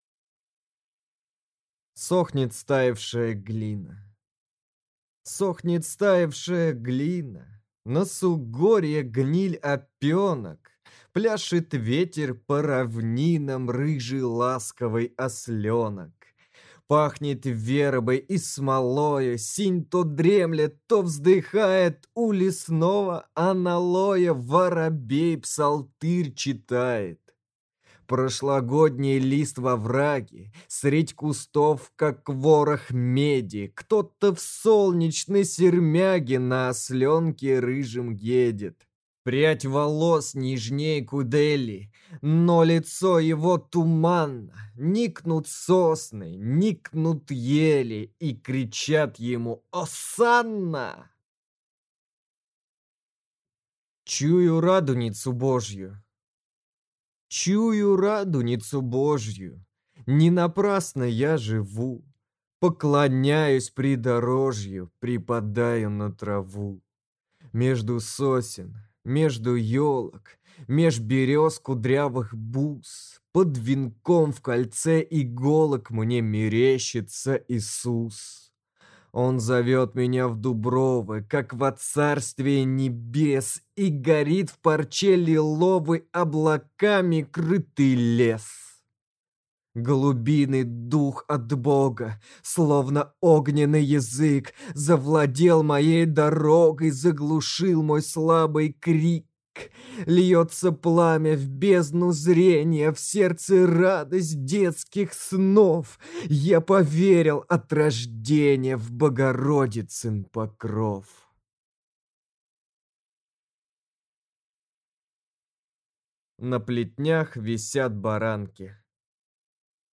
Аудиокнига Анна Снегина. Стихотворения (сборник) | Библиотека аудиокниг